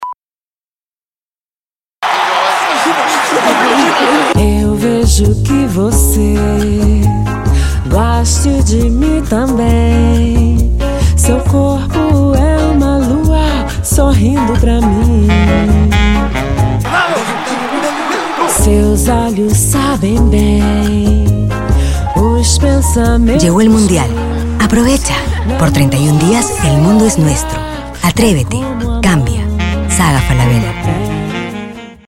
Português - Brasileiro
Saga Falabella Mundial- Voz Mulher Adulta